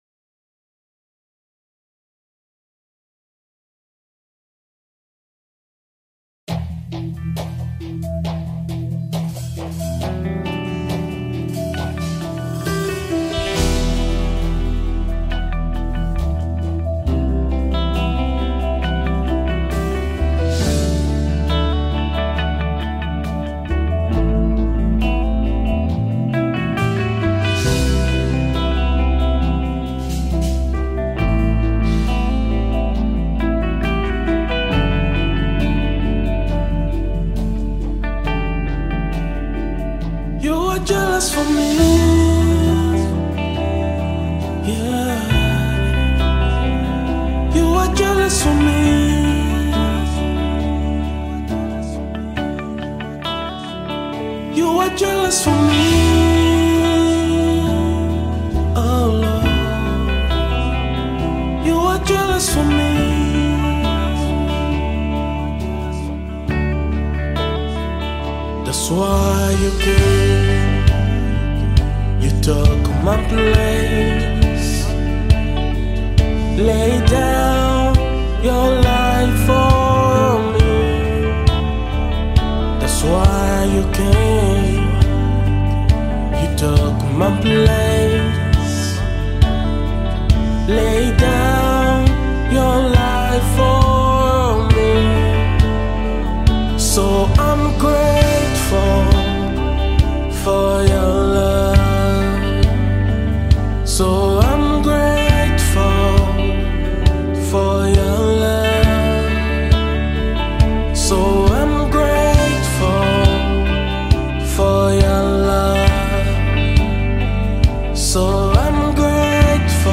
gospel music
uplifting worship song